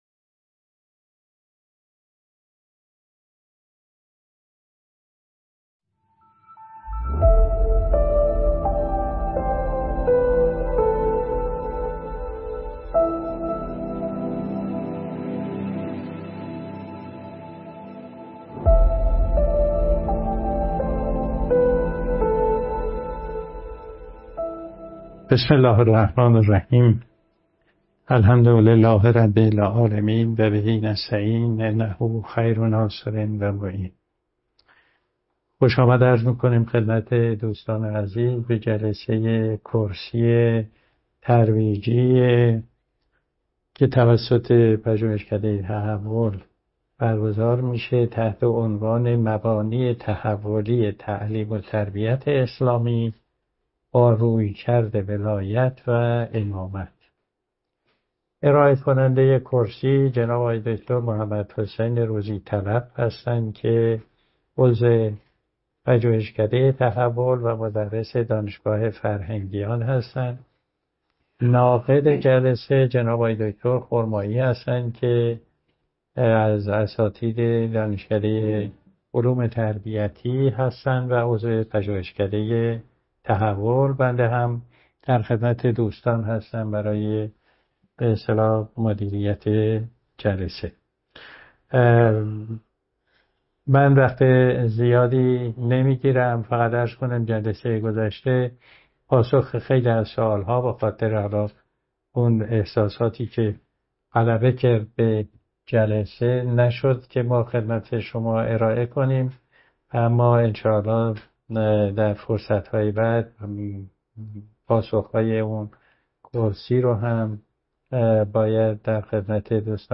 در سالن پژوهشکده تحول در علوم انسانی دانشگاه شیراز برگزار گردید.